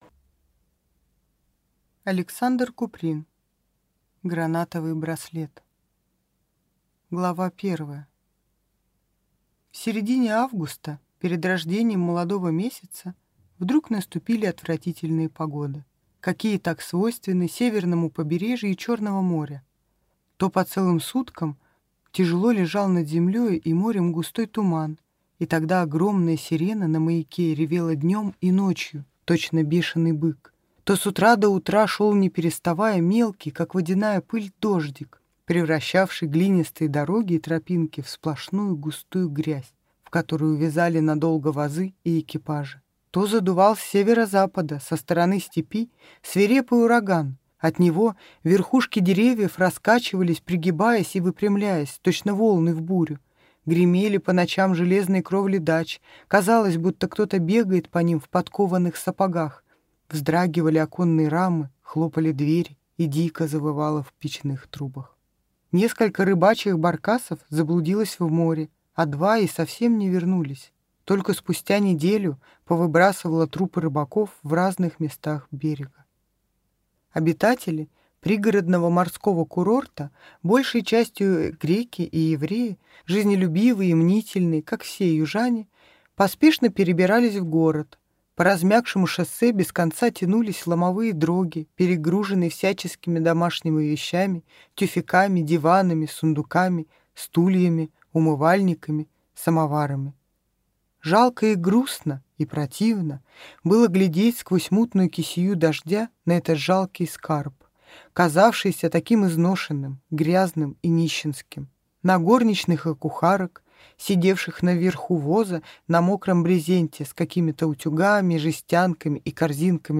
Аудиокнига Гранатовый браслет | Библиотека аудиокниг